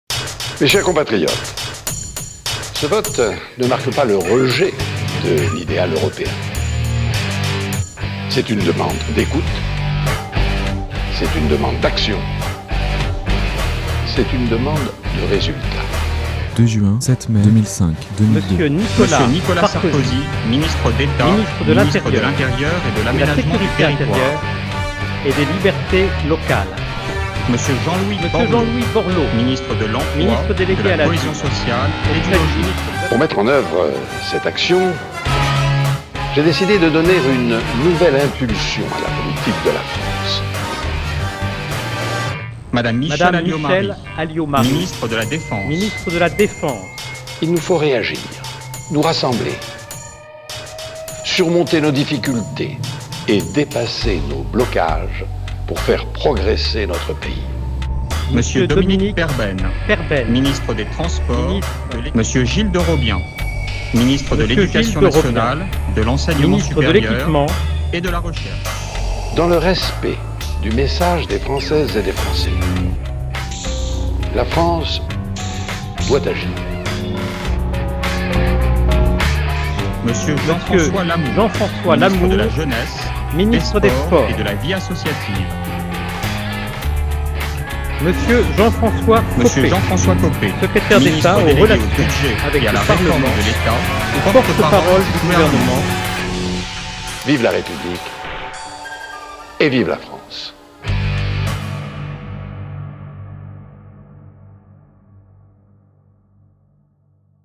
Les Mixs et les bidouillages sonores des oreilles... ce sont des délires de quelques minutes.